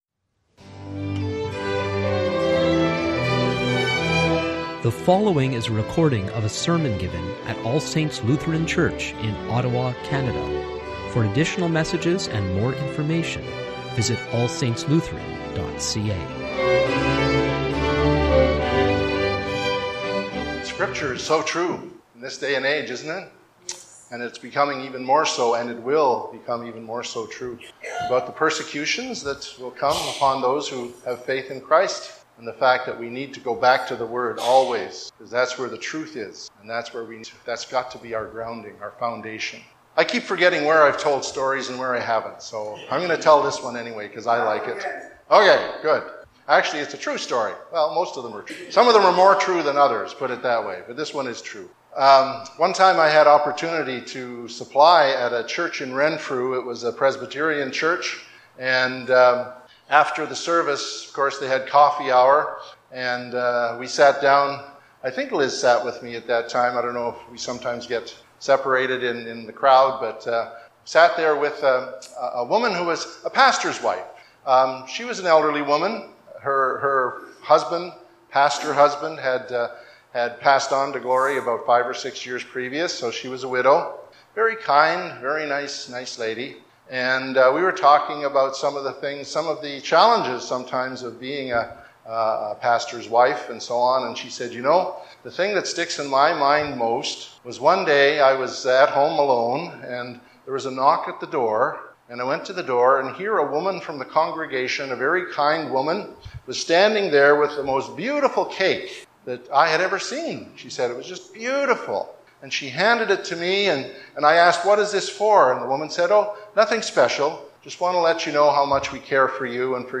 Sermons | All Saints Lutheran Church